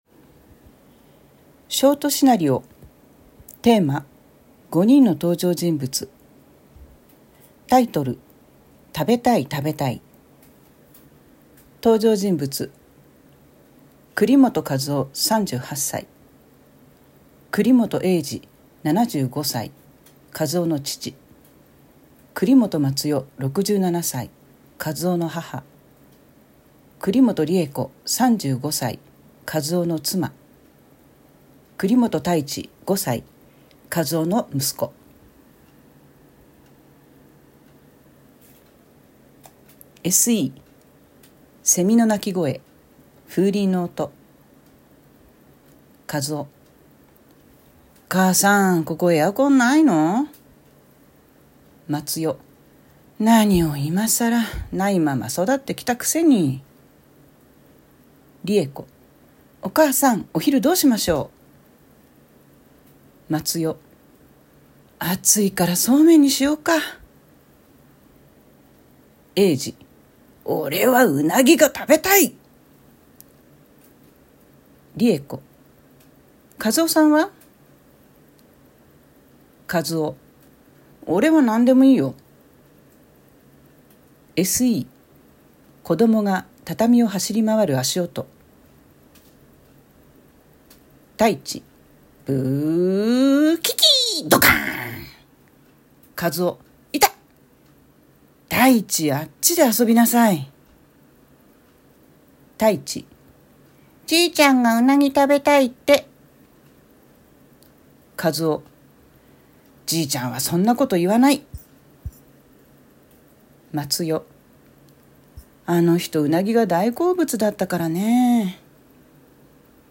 習作朗読『食べたい 食べたい』